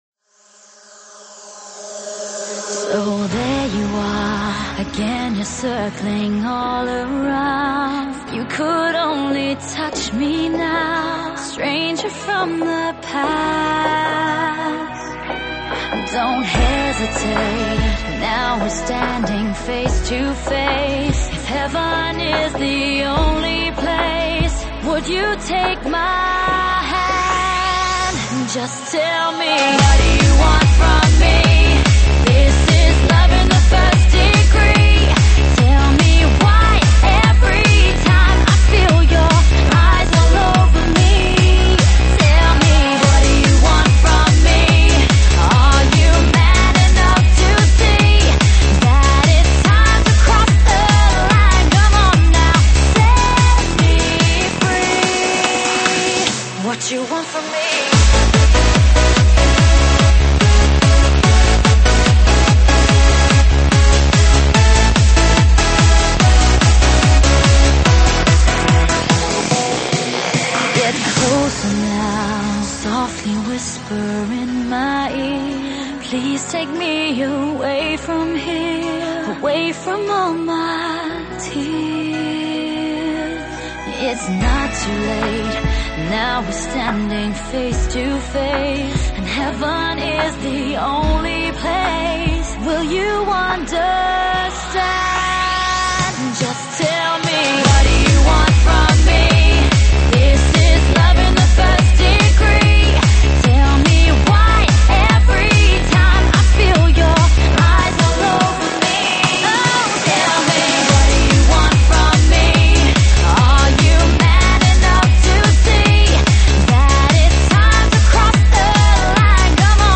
舞曲类别：新年喜庆